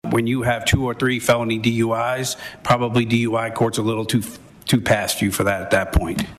CLICK HERE to listen to commentary from Senate Sponsor Paul Rosino.